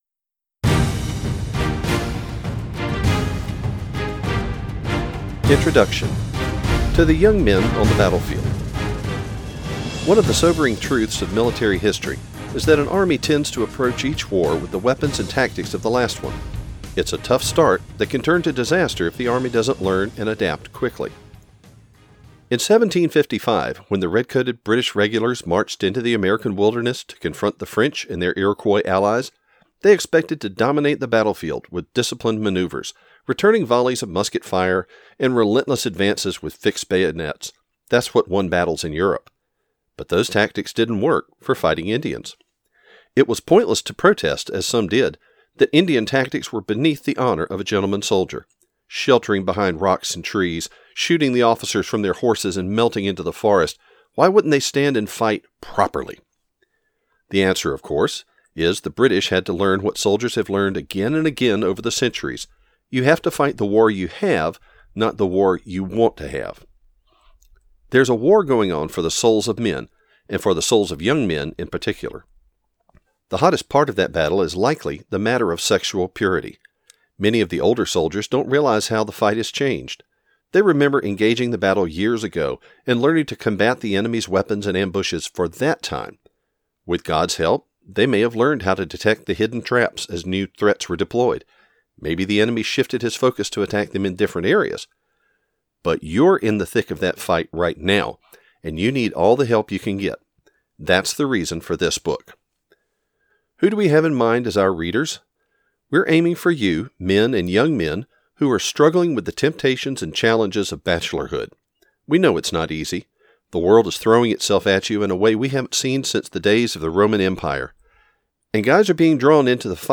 Genre: Audiobook.